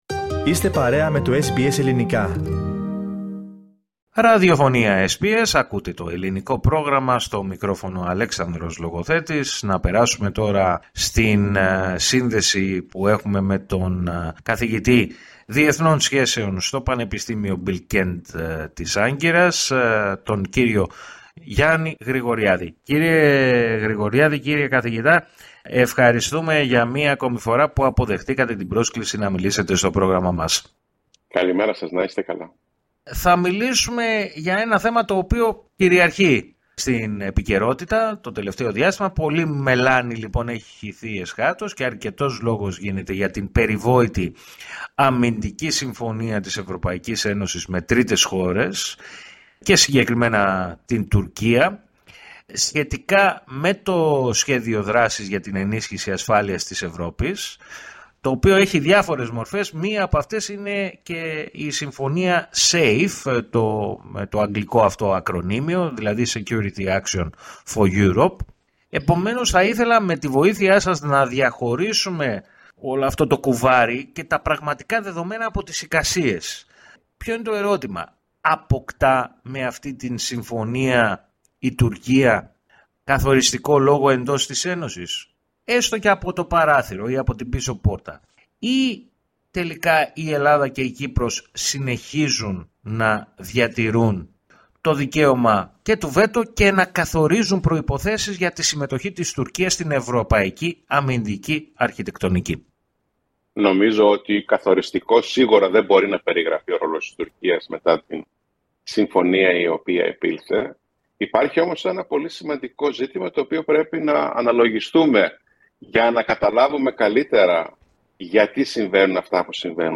Πολύ μελάνι έχει χυθεί, εσχάτως, και αρκετός λόγος γίνεται για την περιβόητη αμυντική συμφωνία της Ευρωπαϊκής Ένωσης με τρίτες χώρες, και συγκεκριμένα την Τουρκία, σχετικά με το Σχέδιο Δράσης για την ενίσχυση ασφάλειας της Ευρώπης, γνωστό και με το αγγλικό ακρωνύμιο SAFE, (Security Action For Europe). Με αυτήν την αφορμή μίλησε στο Ελληνικό Πρόγραμμα της ραδιοφωνίας SBS, o καθηγητής Διεθνών Σχέσεων